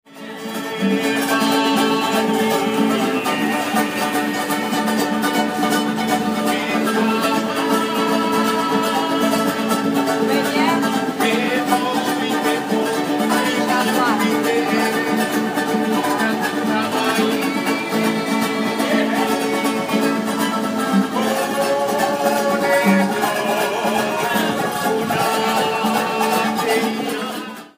Guys playing the Tahitian Ukulele
Recorded in the streets of Papeete with ordinary IPhone 7.